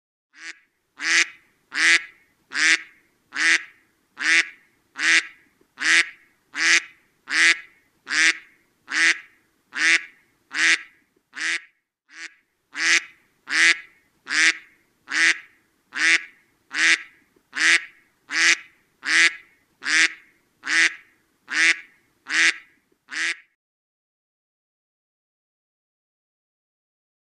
دانلود آهنگ اردک 1 از افکت صوتی انسان و موجودات زنده
جلوه های صوتی
دانلود صدای اردک 1 از ساعد نیوز با لینک مستقیم و کیفیت بالا